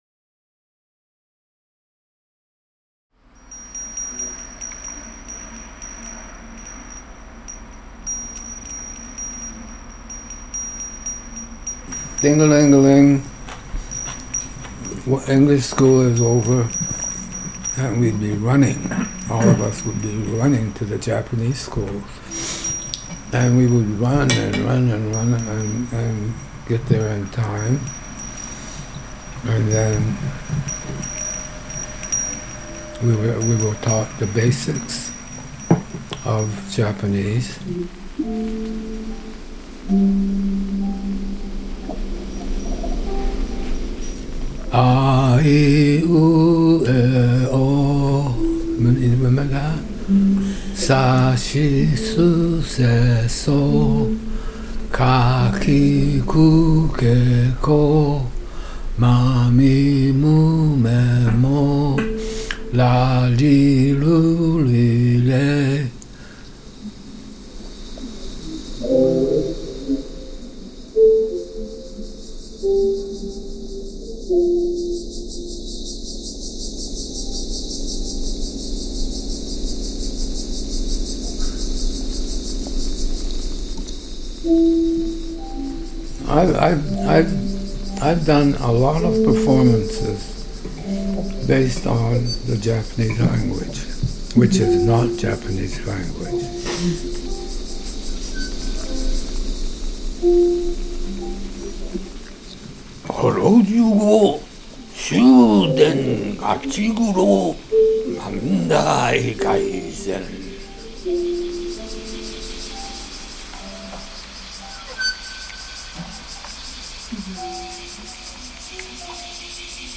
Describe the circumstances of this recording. Nighttime soundscape in Wakimachi